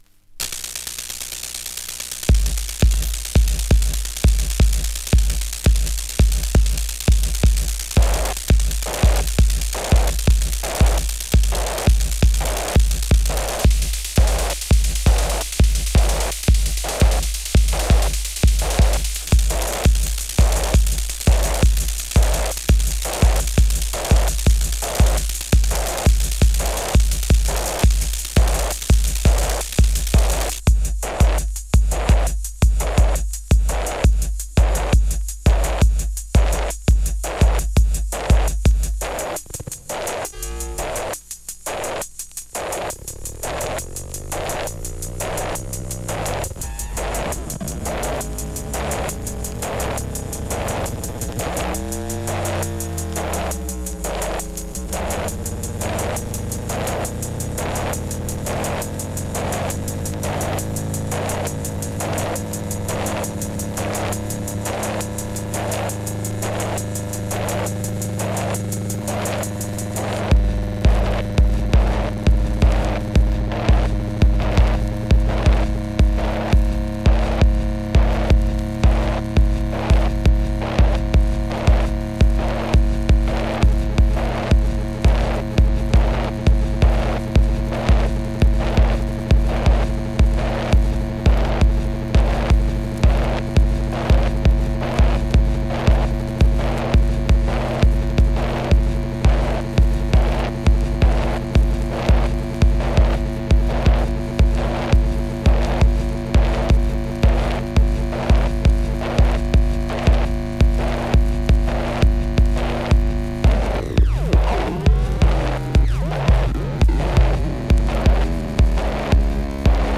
荒れ果てたビート、美しく躍動的なレンジ、圧倒的な純粋音像のエクスペリメンタル・ミニマル。